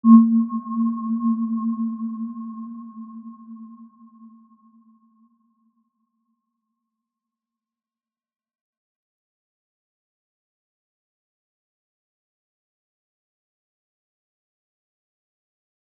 Aurora-C4-mf.wav